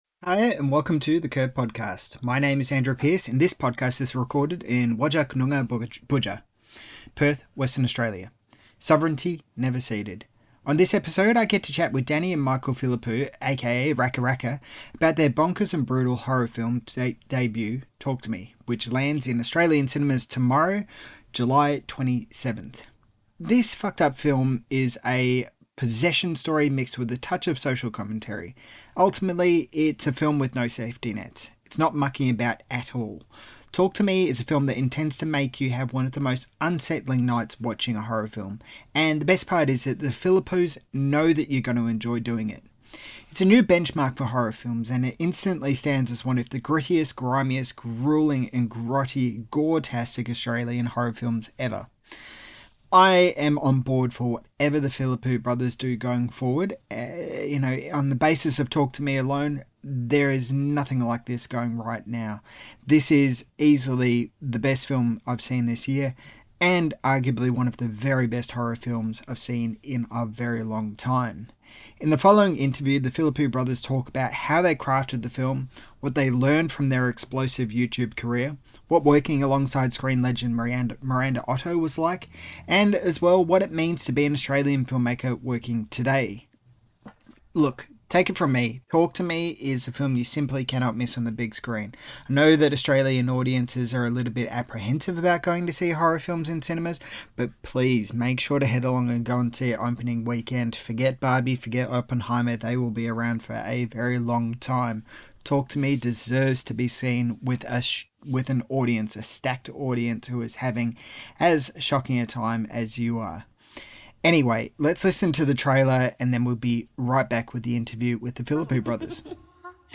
In the following interview, the Philippou brothers talk about how they crafted the film, what they learned from their explosive YouTube career, what working with screen legend Miranda Otto was like, and what it means to be an Australian filmmaker working today.